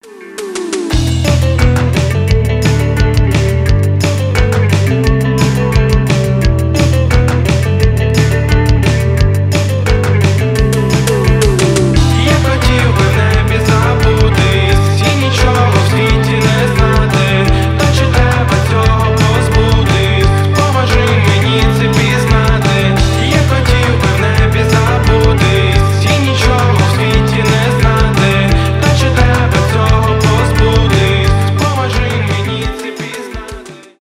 рок
пост-панк